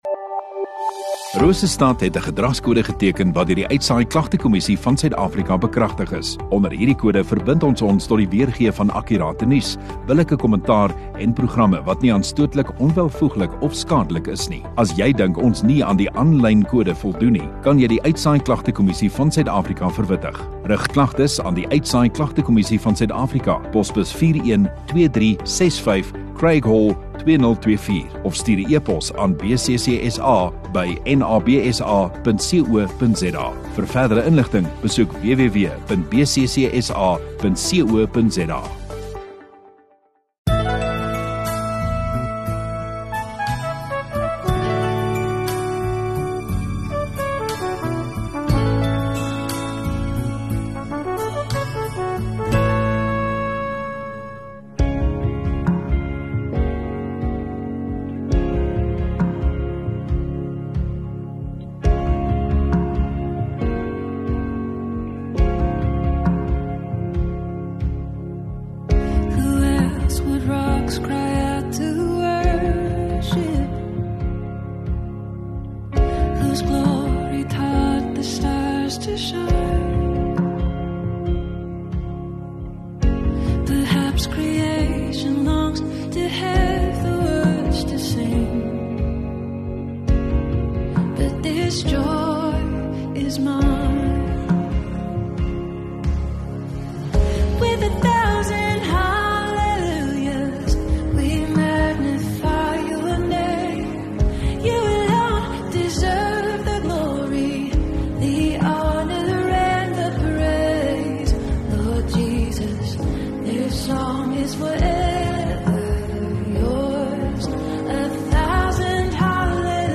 20 Apr Sondagoggend Erediens